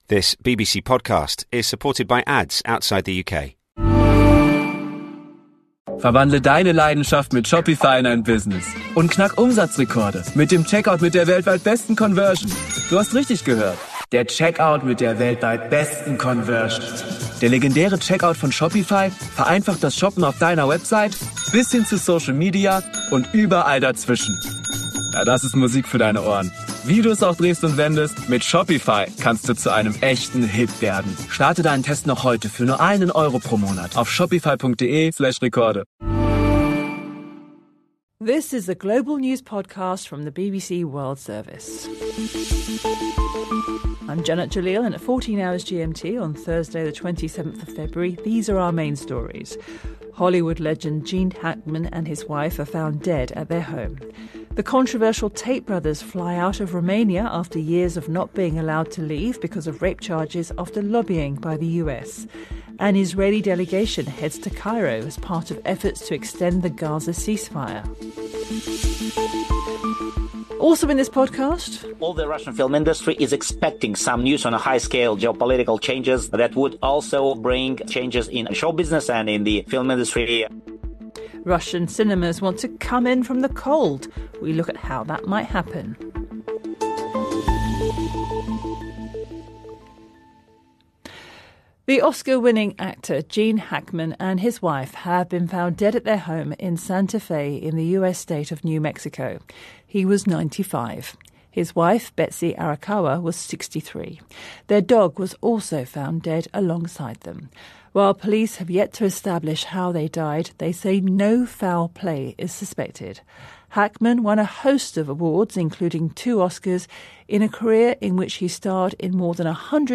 The best stories, interviews and on the spot reporting from around the world including highlights from News hour, The World Today and World Briefing. Up to 30 minutes compiled twice a day from the 24 hour News coverage from the BBC World Service.